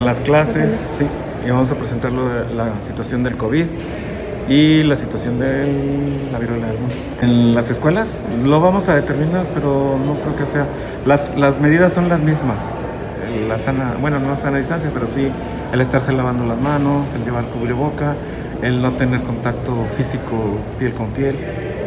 Audio. Secretario de Salud, Felipe Fernando Sandoval Magallanes.
Sandoval-Magallanes-sobre-Consejo-Estatal-de-Salud.mp3